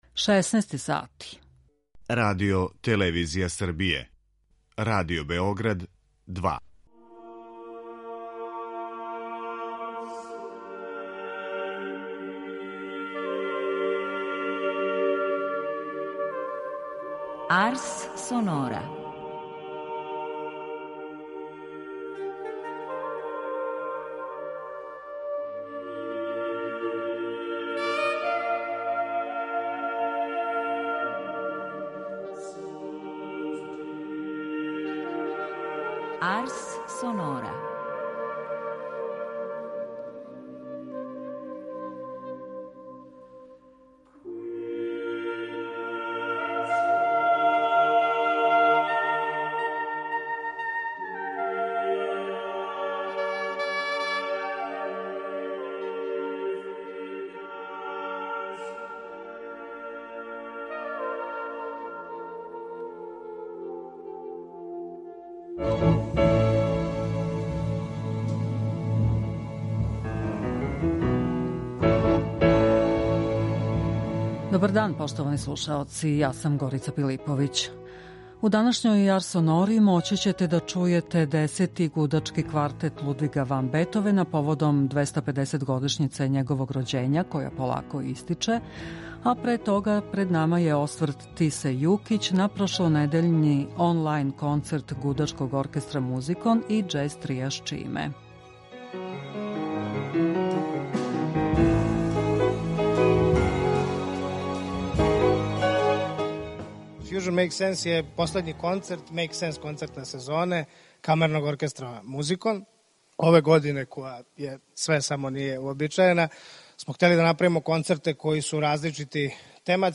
У делу емисије посвећеном обележавању 250-годишњице Лудвига ван Бетовена слушаћемо његов први гудачки квартет.